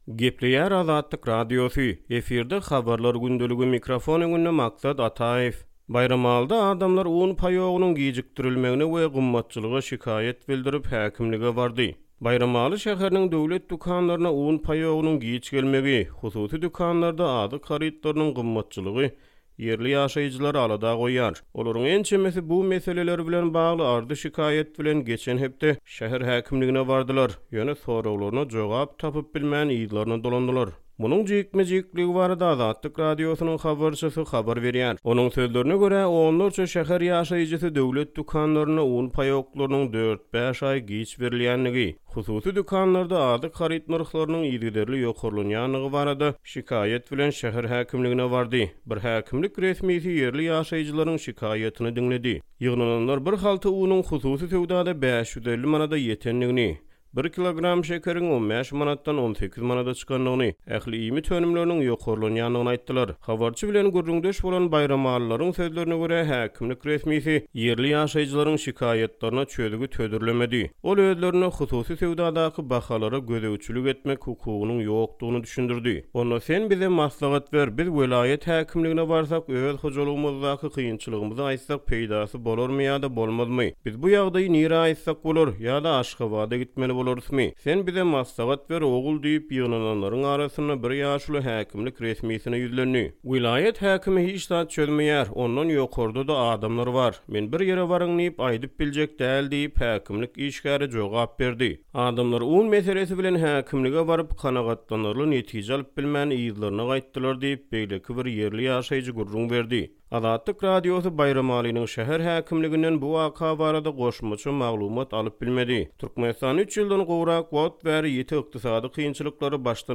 Munuň jikme-jikligi barada Azatlyk Radiosynyň habarçysy habar berýär